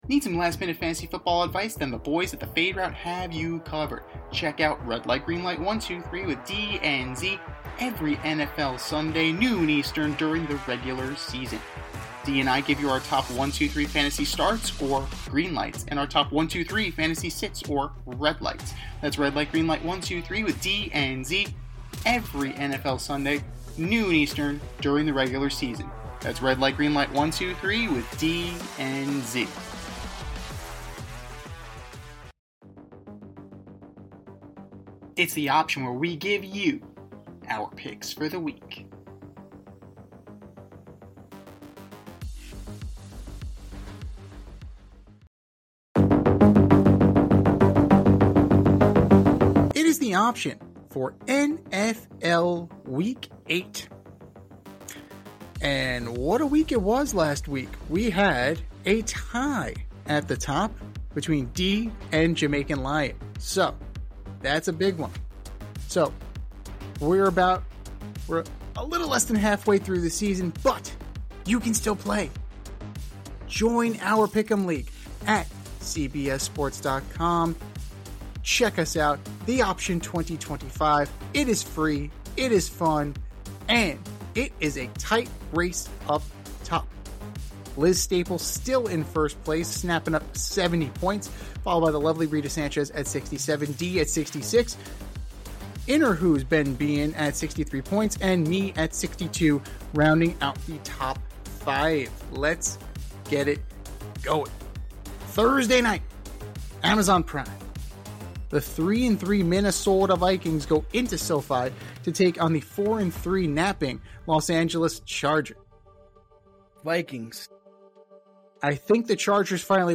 two veteran sports aficionados and lifelong friends